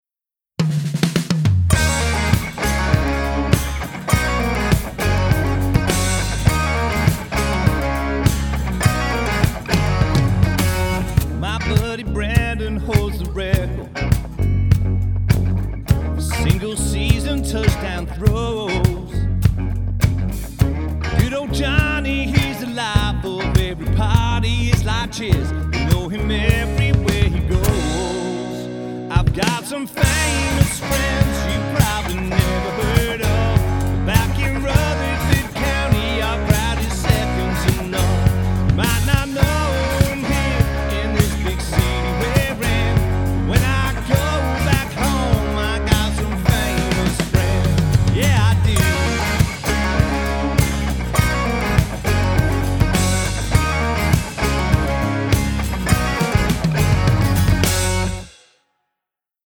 Big 'Bro Country' Hits Band with One Epic Show.
Get ready for an all-male country party band.